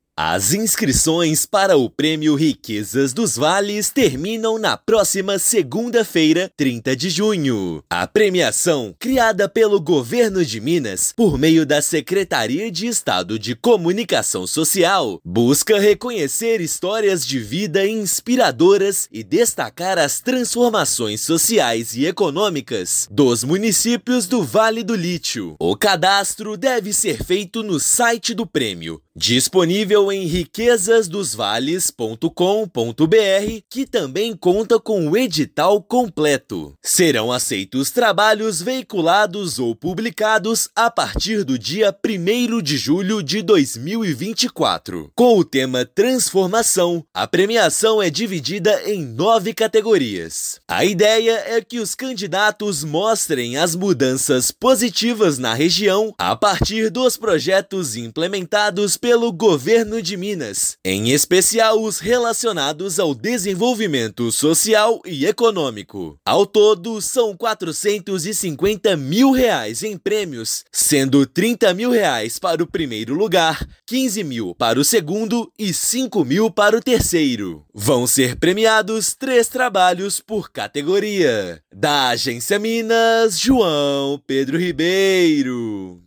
[RÁDIO] Inscrições para o prêmio Riquezas dos Vales terminam na segunda-feira (30/6)
São R$ 450 mil em prêmios destinados para produções que promovam a cultura e a identidade da região do Vale do Lítio. Ouça matéria de rádio.